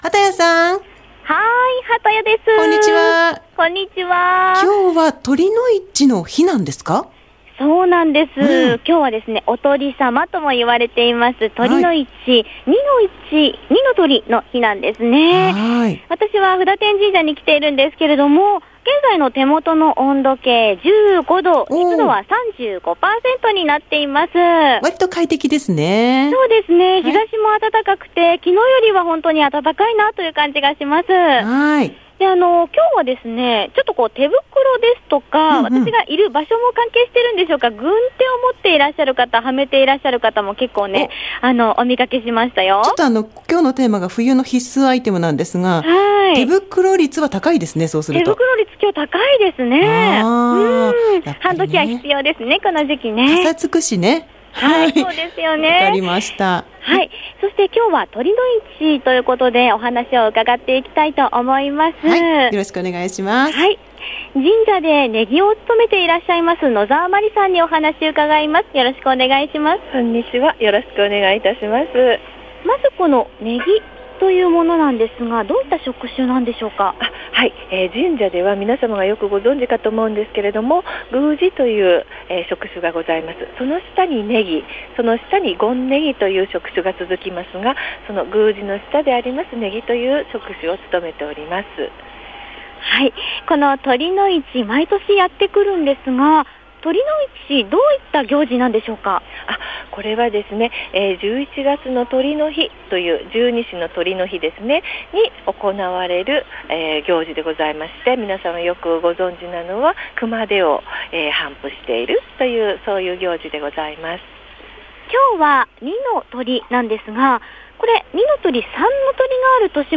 午後のカフェテラス　街角レポート
露店の威勢のいい声に包まれてましたぁ♪
布多天神社の酉の市「二の酉」へ行ってきましたよぉ！
拍子木を鳴らしたり手を打ってくれたりするんですぅ！
３本締め☆☆☆
福の神が飛んできそうな生きの良いかけ声でした！